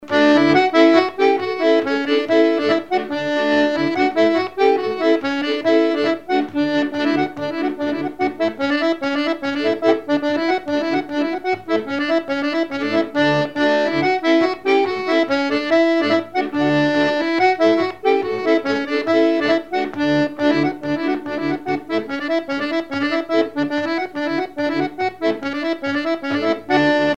Chants brefs - A danser
instrumentaux à l'accordéon diatonique
Pièce musicale inédite